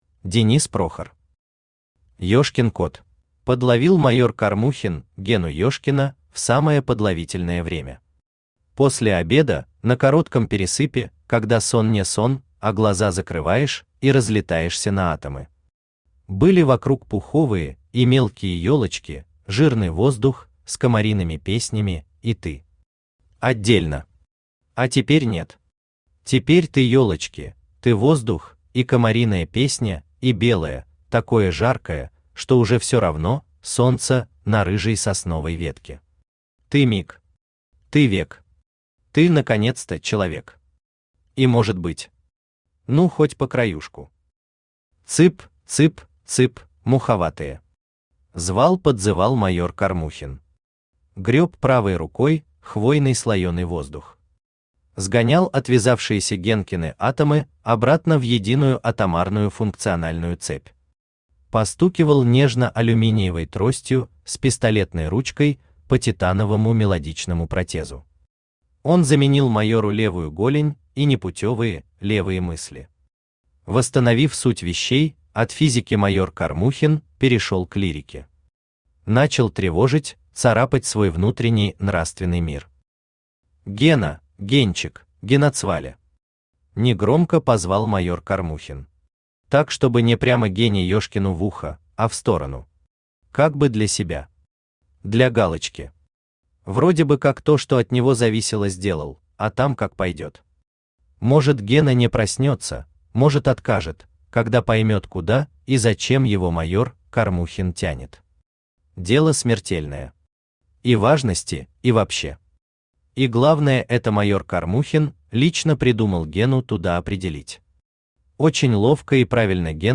Аудиокнига Ешкин код | Библиотека аудиокниг